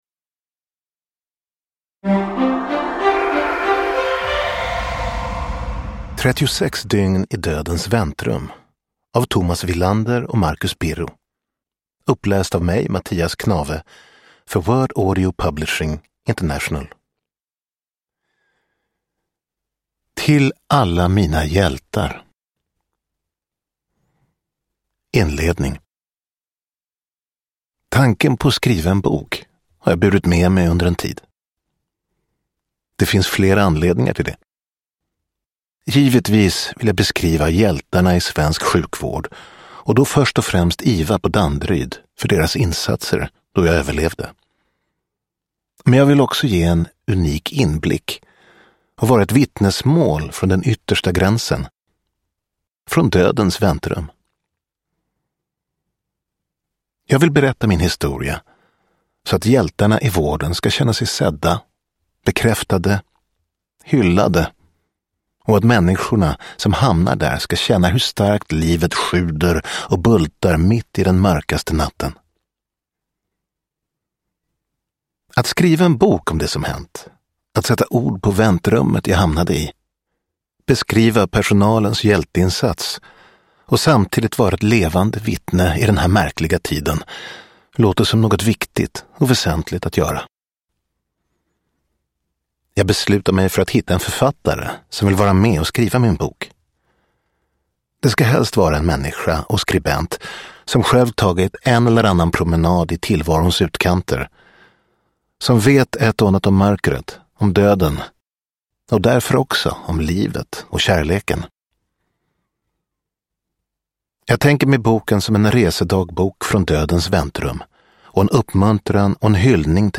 36 dygn i dödens väntrum (ljudbok) av Marcus Birro